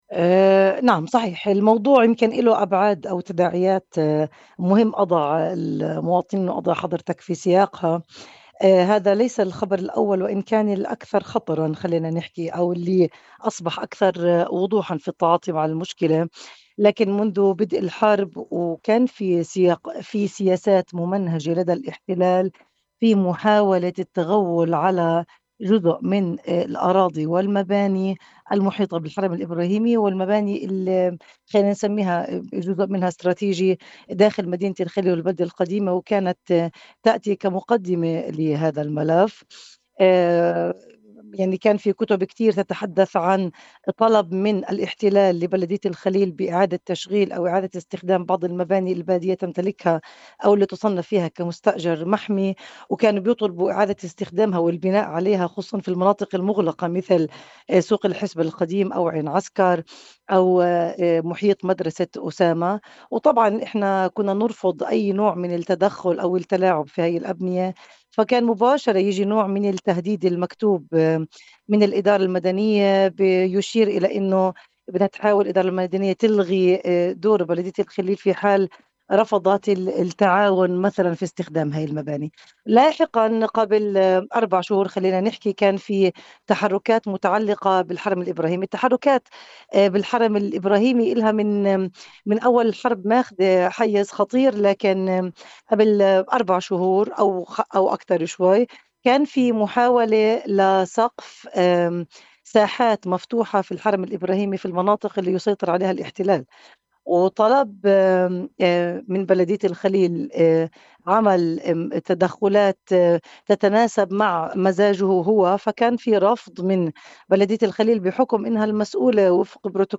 نائبة رئيس بلدية الخليل لإذاعة “قناة القدس”: الاحتلال ينفذ خطوات غير مسبوقة لتغيير الوضع القائم في الحرم الإبراهيمي
وفي تصريحات خاصة لإذاعة قناة القدس، قالت شرباتي إن “الاحتلال مارس سياسات ممنهجة للتغول على مساحات أراضٍ ومبانٍ محيطة بالحرم الإبراهيمي”، مشيرة إلى أن بلدية الخليل رفضت مطالب الاحتلال المتعلقة بتعديلات هندسية داخل الحرم تخدم أجندات المستوطنين.